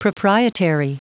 Prononciation du mot proprietary en anglais (fichier audio)
Prononciation du mot : proprietary